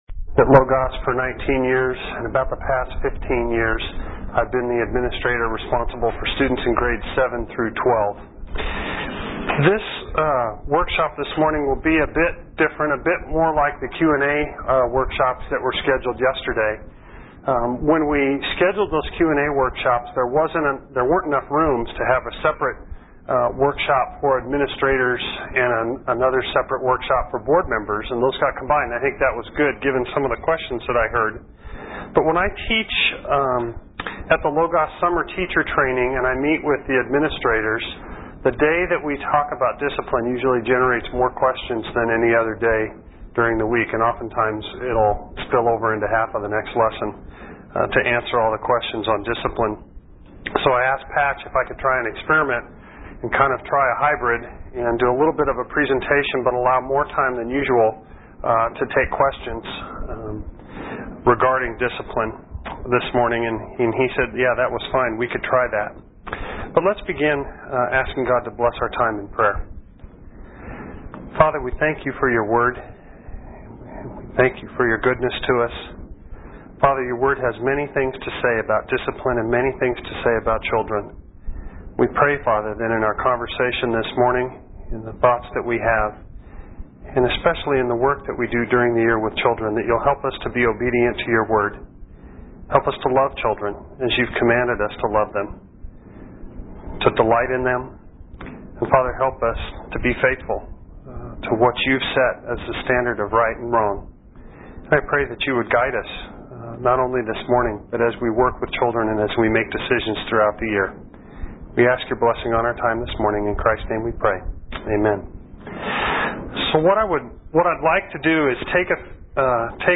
2003 Foundations Talk | 0:59:13 | 7-12, Virtue, Character, Discipline
Mar 11, 2019 | 7-12, Conference Talks, Foundations Talk, Library, Media_Audio, Virtue, Character, Discipline | 0 comments
The Association of Classical & Christian Schools presents Repairing the Ruins, the ACCS annual conference, copyright ACCS.